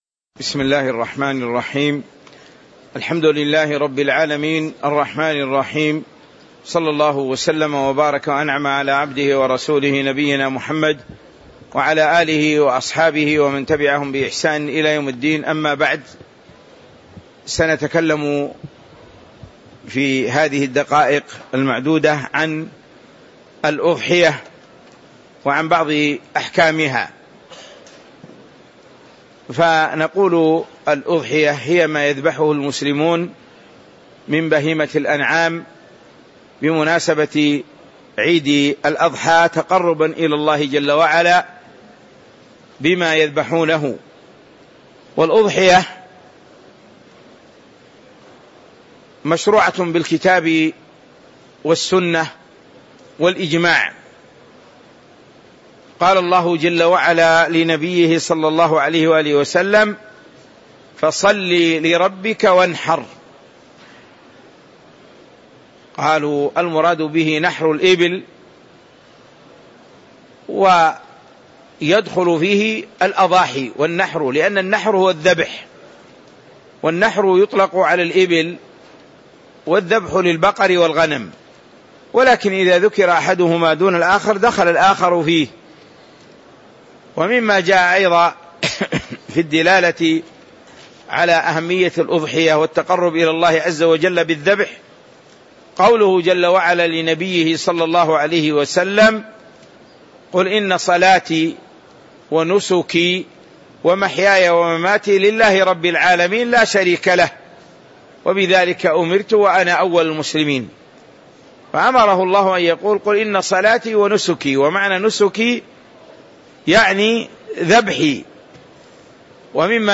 تاريخ النشر ٥ ذو الحجة ١٤٤٦ هـ المكان: المسجد النبوي الشيخ